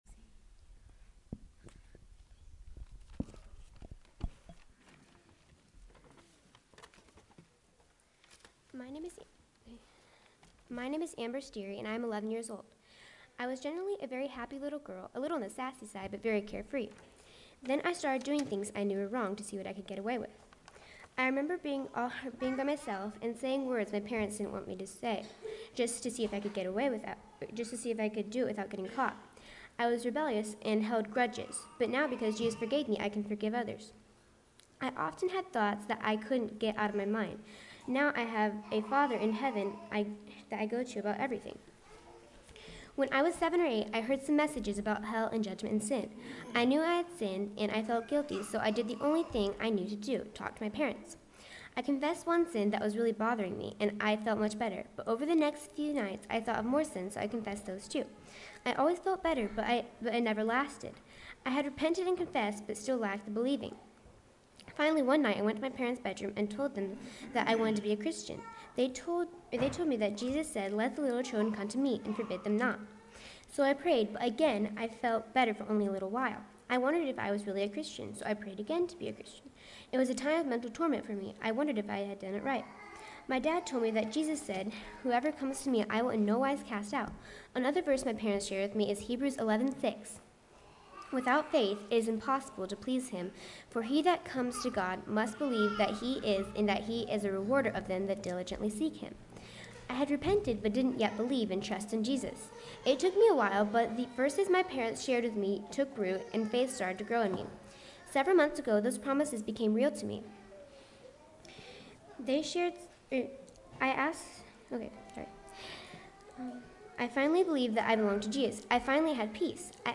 Baptismal Testimony